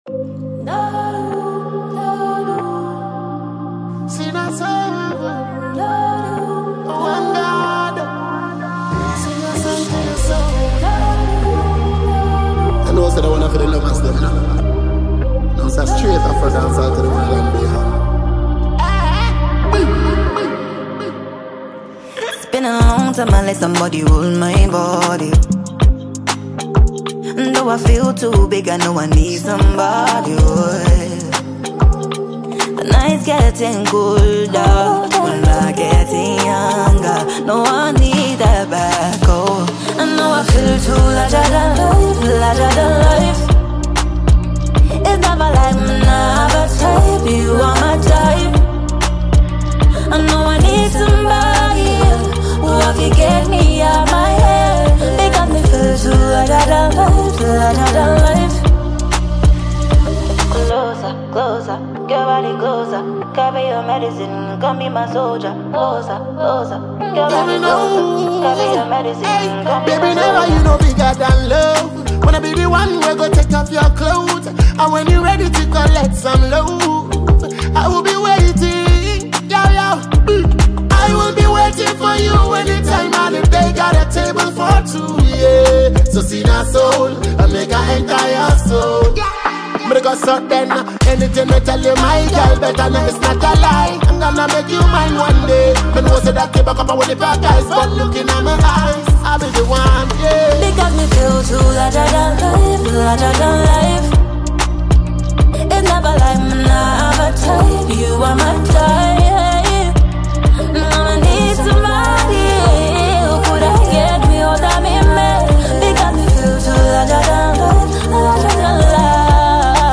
Ghanaian female singer and songwriter
Award-winning Afro-dancehall musician